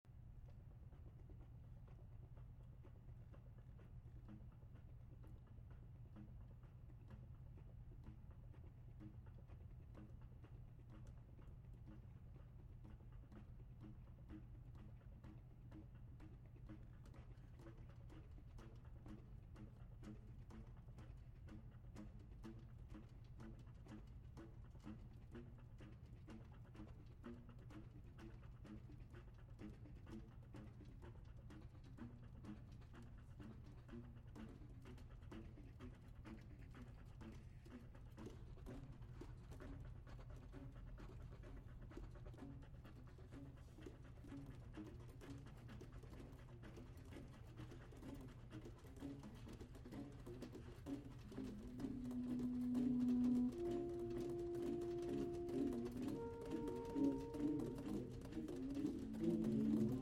Saxophone Ensemble
Soprano Saxophone
Alto Saxophone
Tenor Saxophone
Baritone Saxophone